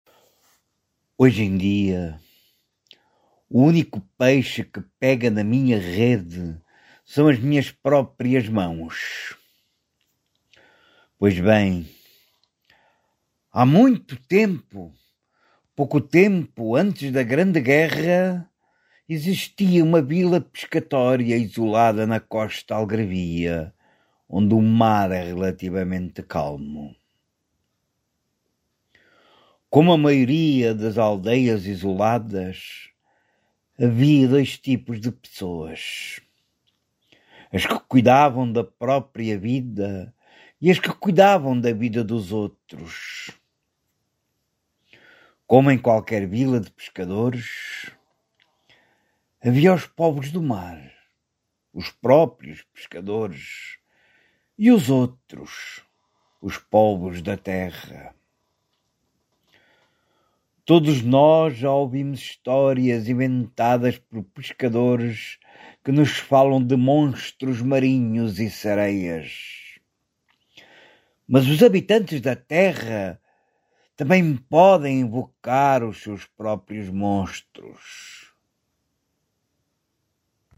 Prólogo em português
Encantador_Opening_Narration.mp3